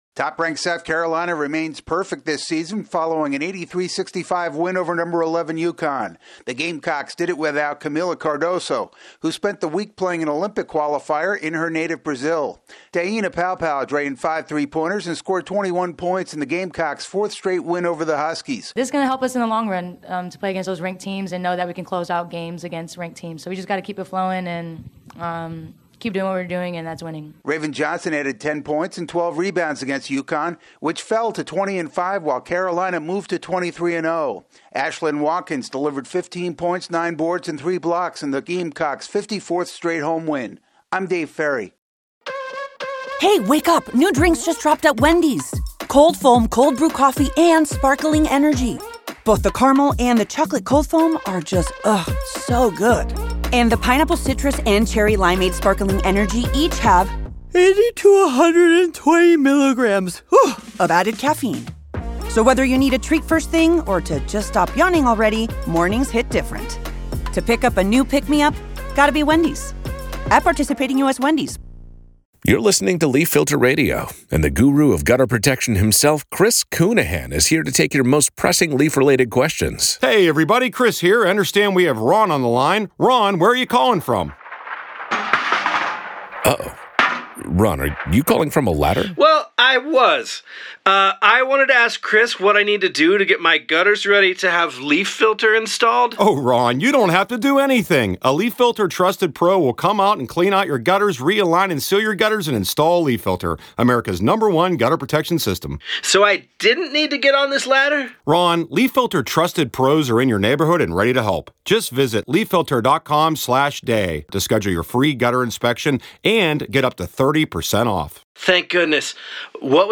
South Carolina handles UConn to stay unbeaten. AP correspondent